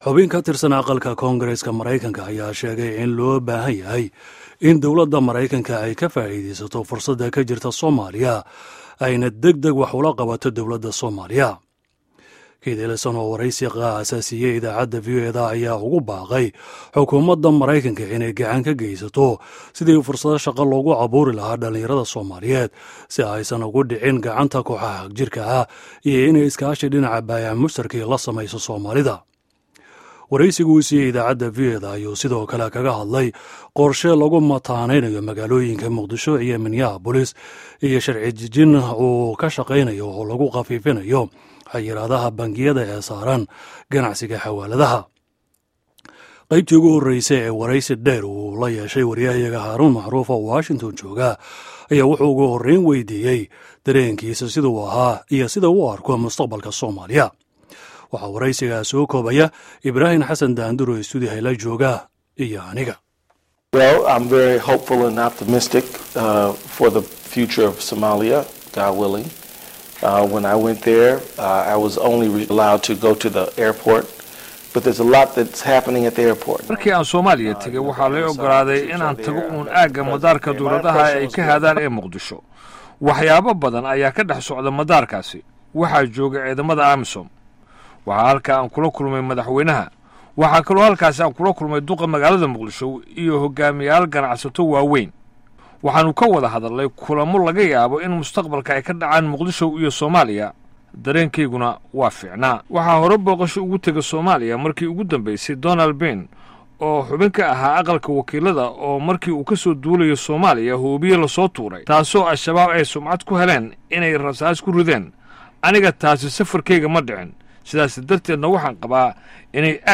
Keith Ellison oo Waraysi Gaar ah Siiyey VOA
Waraysiga Keith Ellison oo Dhameystiran